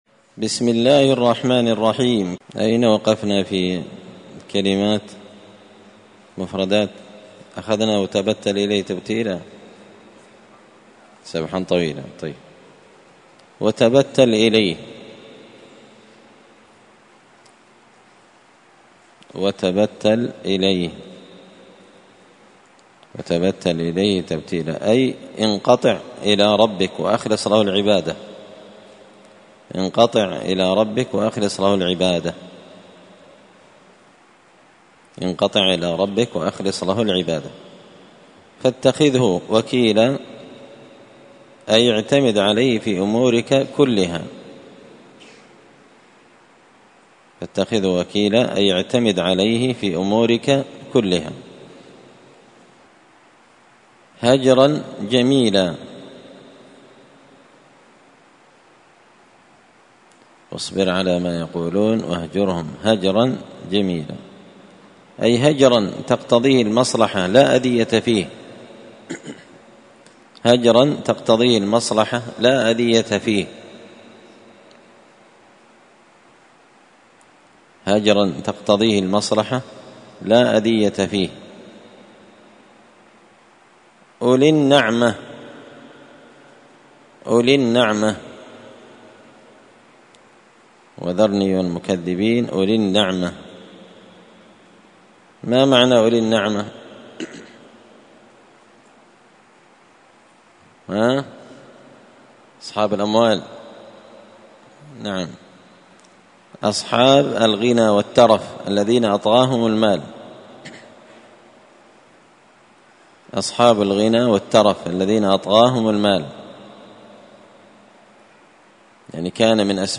(جزء تبارك سورة المزمل الدرس 90)